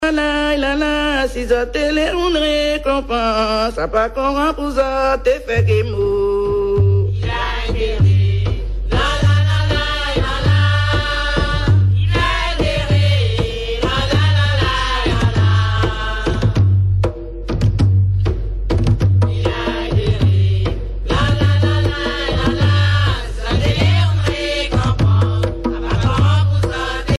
Mémoires et Patrimoines vivants - RaddO est une base de données d'archives iconographiques et sonores.
danse : grajé (créole)
Pièce musicale inédite